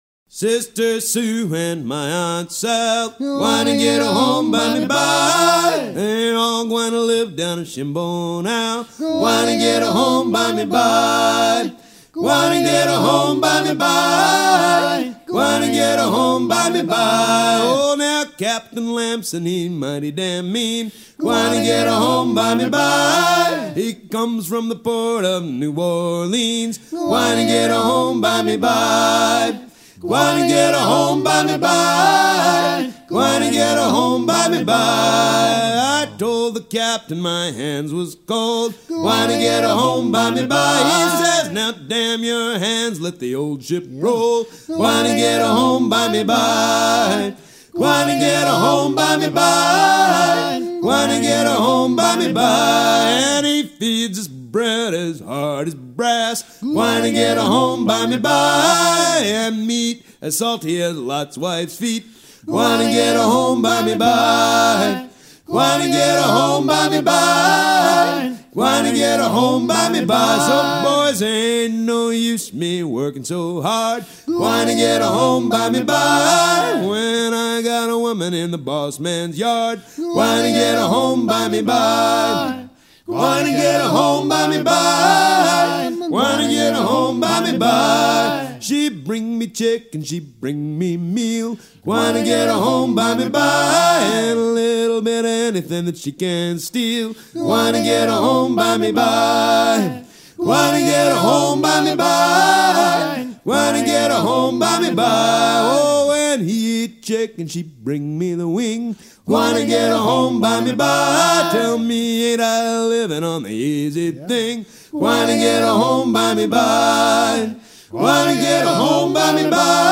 gestuel : à pomper ; gestuel : à virer au cabestan ;
Pièce musicale éditée